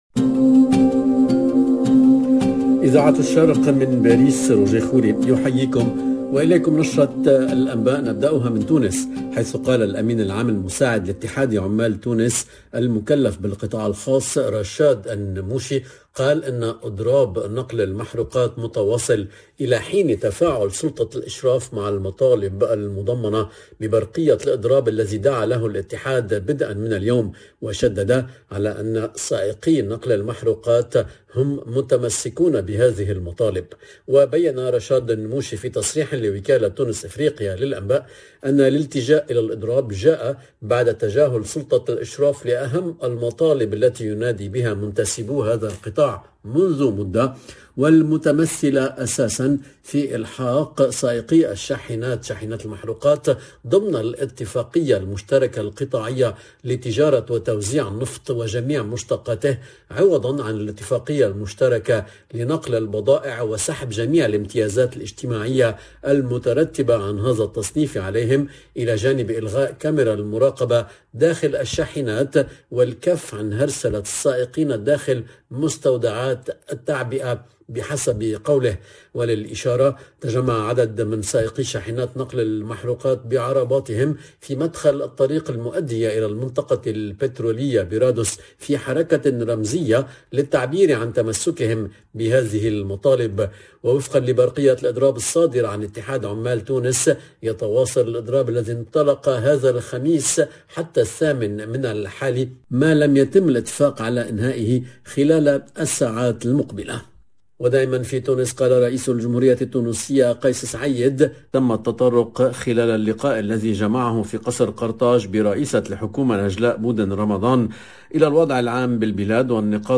LE JOURNAL DU SOIR EN LANGUE ARABE DU 6/01/22